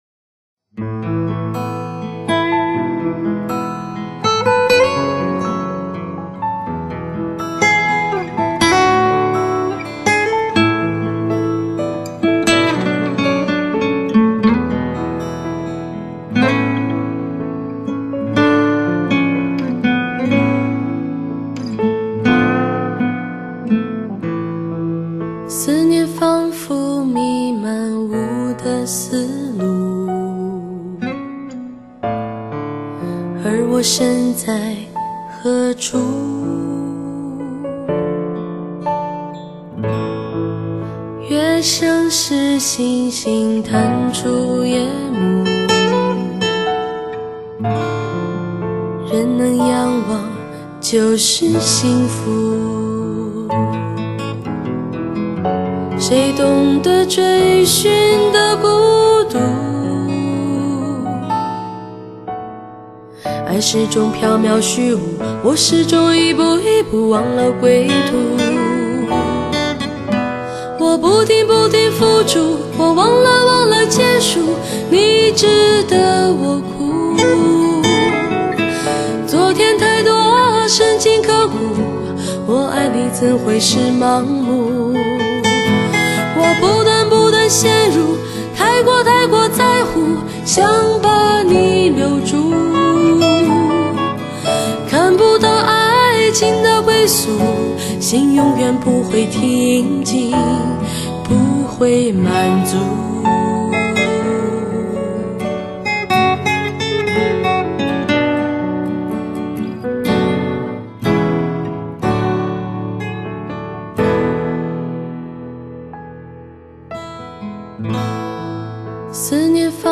年轻精英的首选，时尚、动感、像风一样自由！